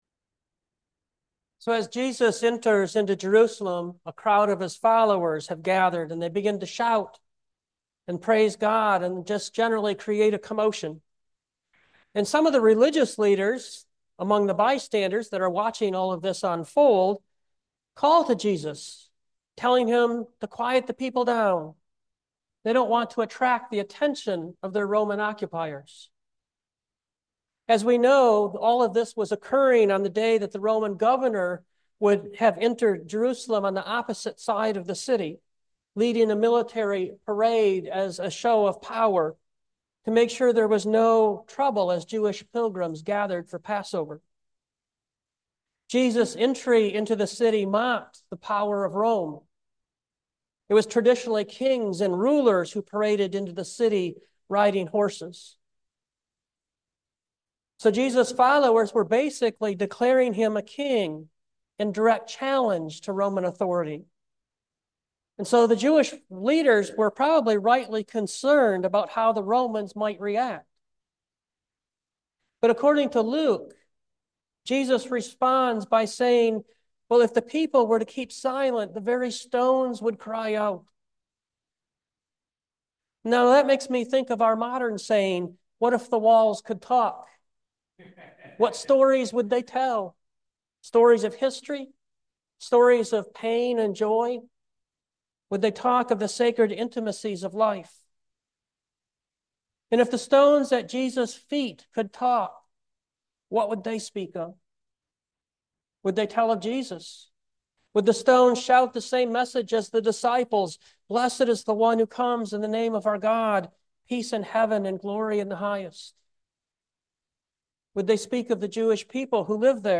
2025 Let the Stones Shout Preacher